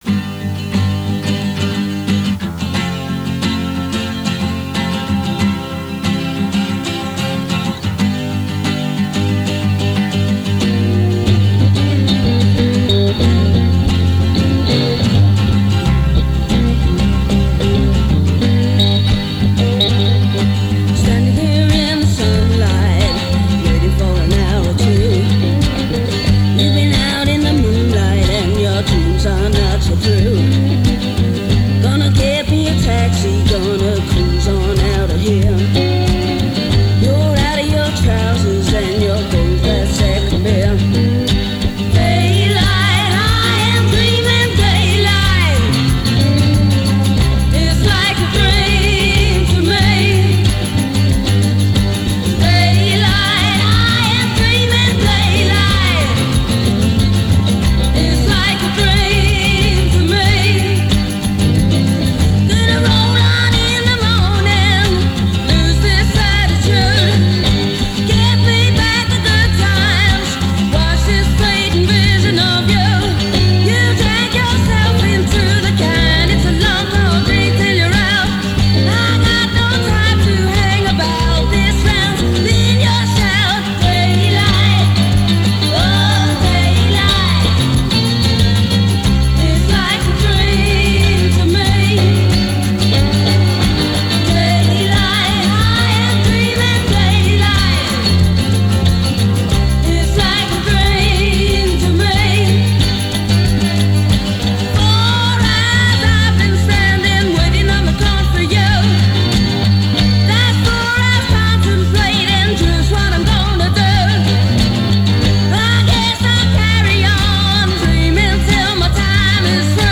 Musik im Stile der 60er/70er
Acoustic-Version
Genre: Pop